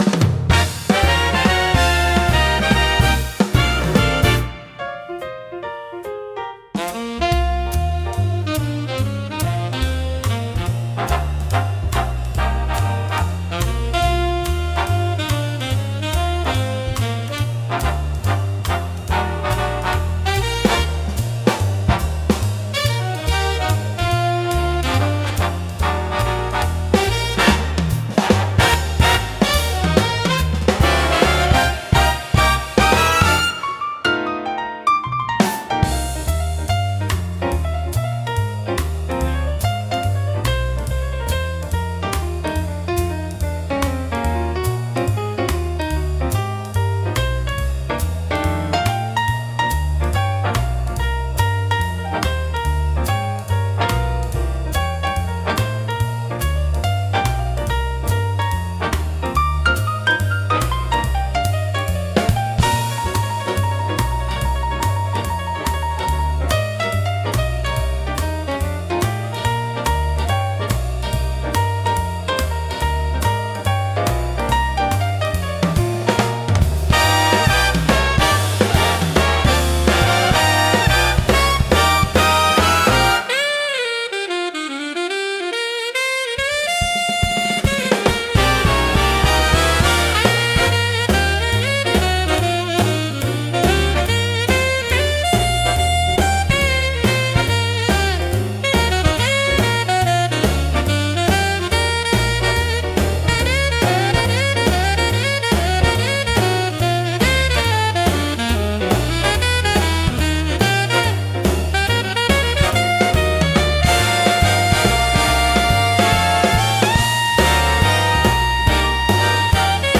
聴く人を楽しい気分にさせる迫力とノリの良さが魅力のジャンルです。